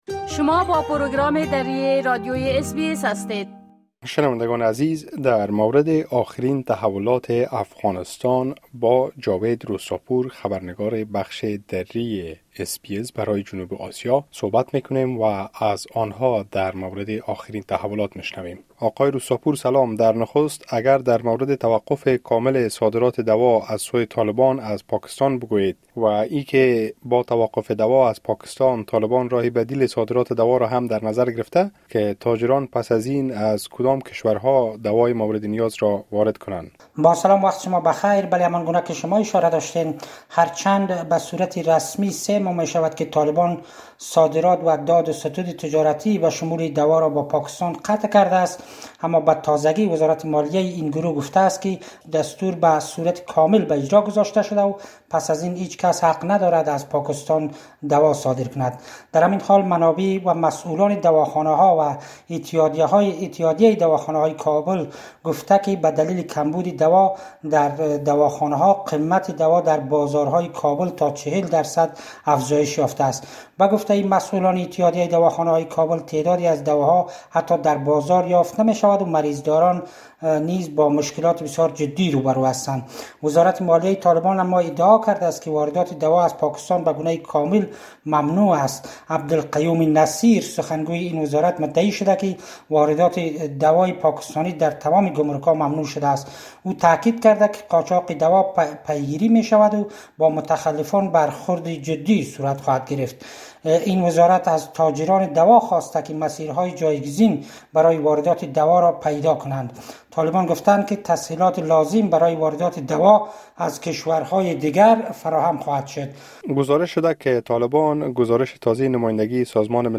گزارش خبرنگار ما برای جنوب آسیا: طالبان واردات دوا از پاکستان را بطور کامل ممنوع کردند